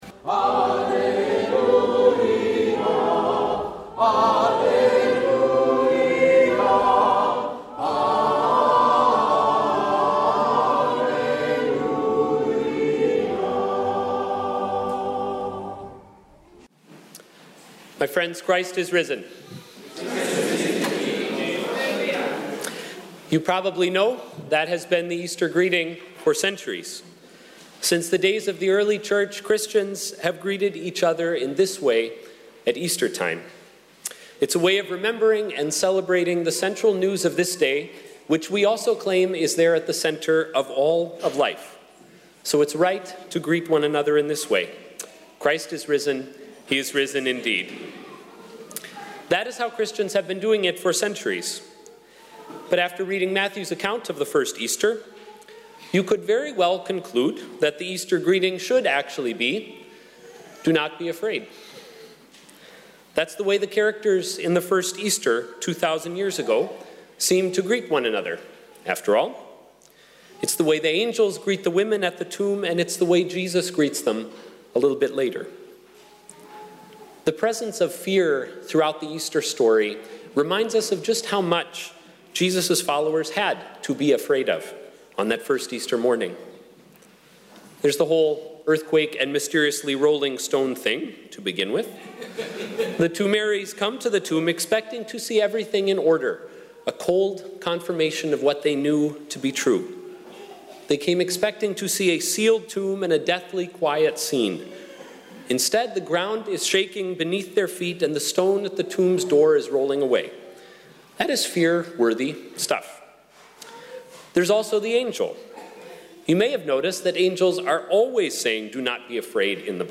2017sermoneastersunday.mp3